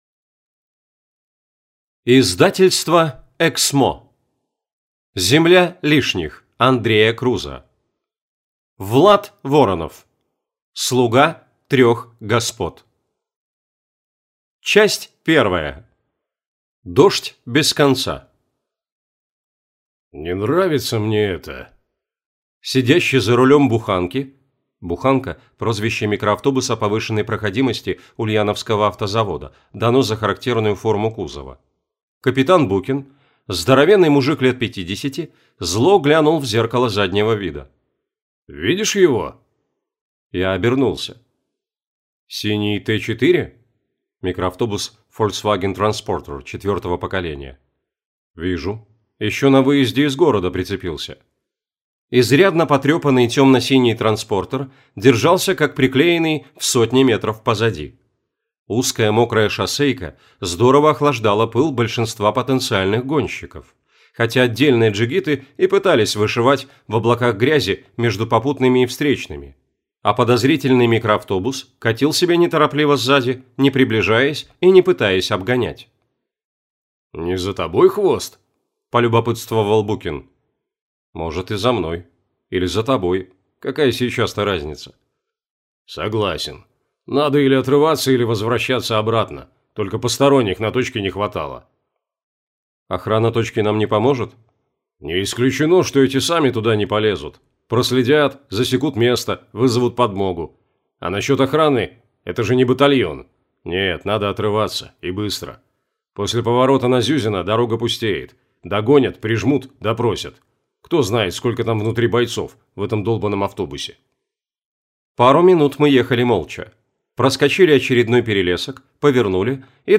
Аудиокнига Земля лишних. Слуга трех господ | Библиотека аудиокниг